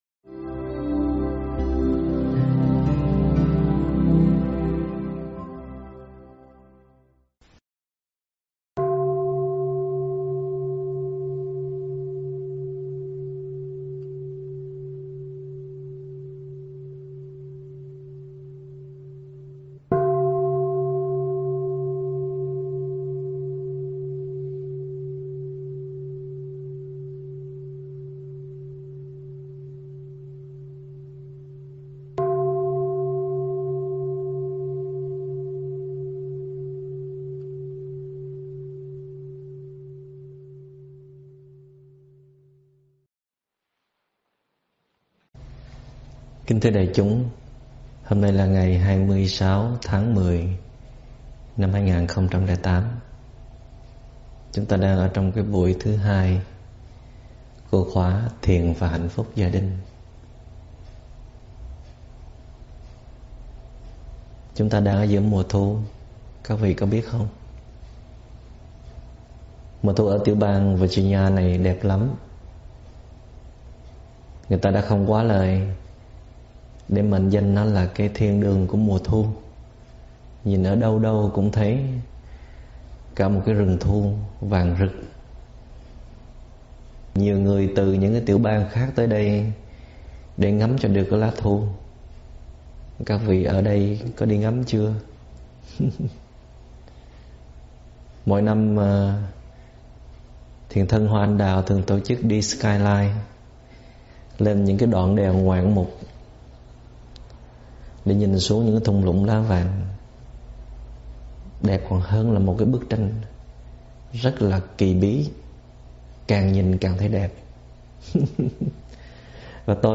Mời quý phật tử nghe mp3 thuyết pháp Nhìn lại gốc rễ của khổ đau do TS. Thích Minh Niệm giảng ngày 26 tháng 10 năm 2008
Thích Minh Niệm giảng ngày 26 tháng 10 năm 2008 Mp3 Thuyết Pháp Thích Minh Niệm 2008 Thuyết pháp Thích Minh Niệm